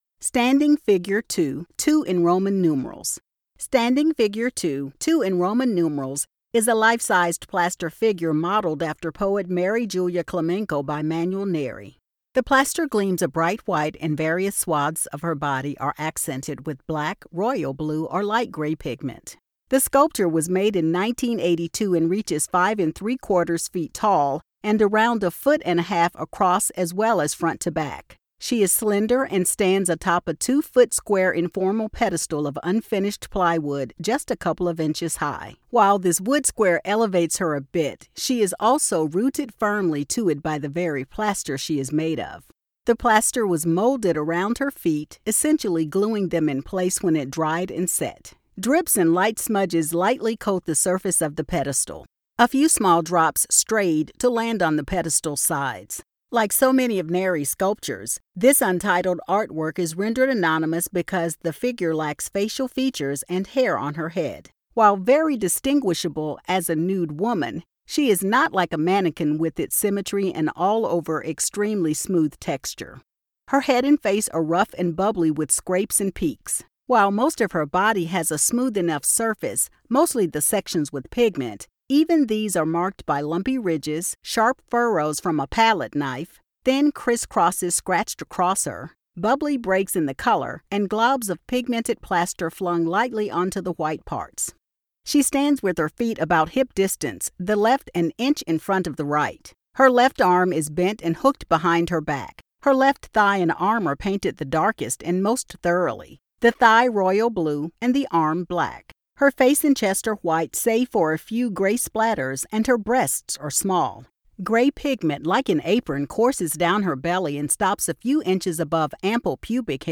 Audio Description (02:37)